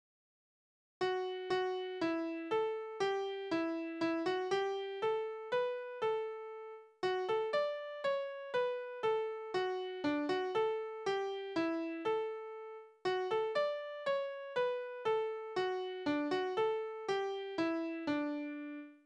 Tonart: D-Dur
Taktart: 3/4
Tonumfang: Oktave
Besetzung: vokal